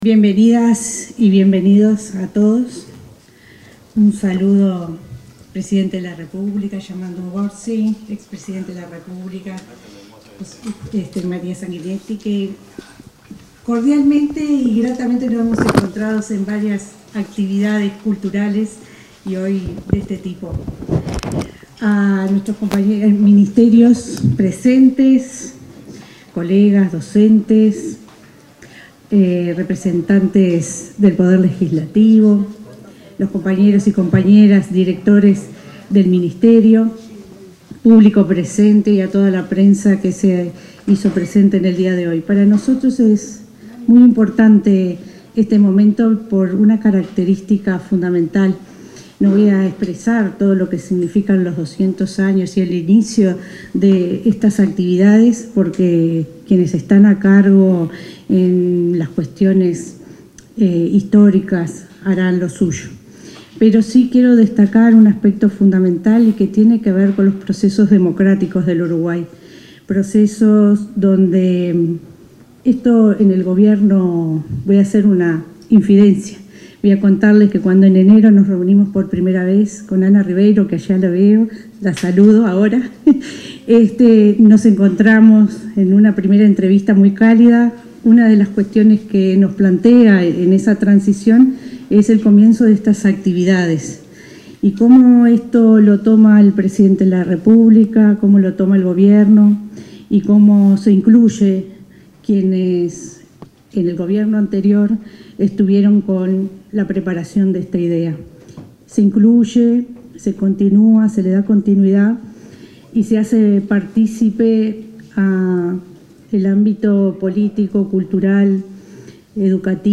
Palabras de la ministra interina de Educación, Gabriela Verde
Palabras de la ministra interina de Educación, Gabriela Verde 20/08/2025 Compartir Facebook X Copiar enlace WhatsApp LinkedIn La ministra interina de Educación y Cultura, Gabriela Verde, expuso en el lanzamiento de las celebraciones de los 200 años del proceso de creación de la República Oriental del Uruguay.